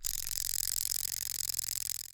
SA_bite_miss.ogg